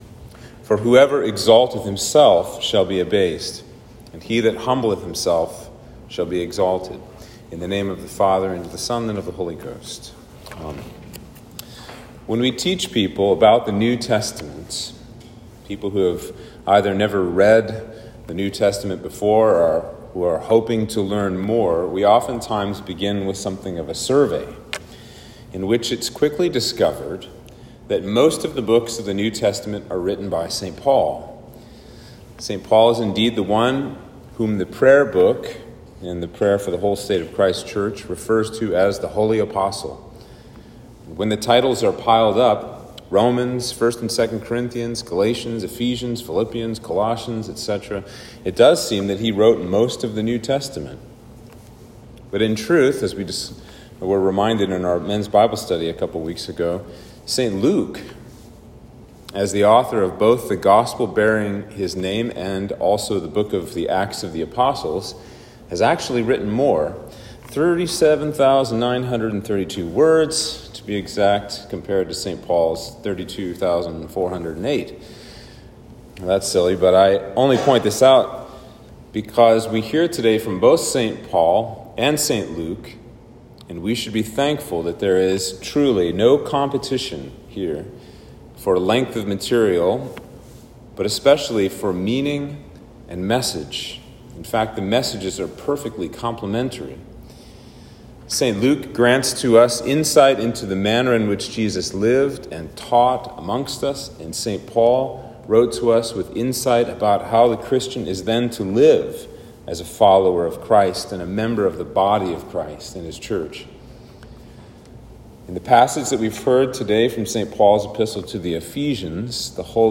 Sermon for Trinity 17